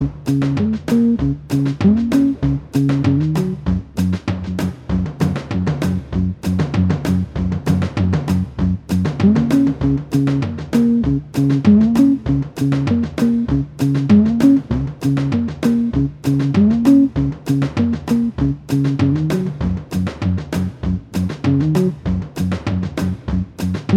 Minus Guitars Rock 'n' Roll 2:19 Buy £1.50